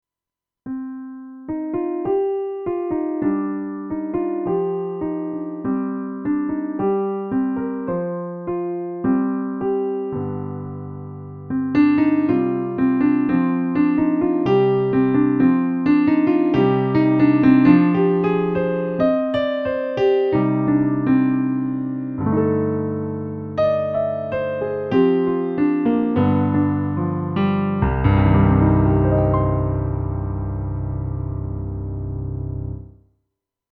I did a bit of one of the pieces of music myself on a Yamaha S90 and compared the recorded wav (uncompressed CD quality audio) and the mpeg compressed result.
pianosound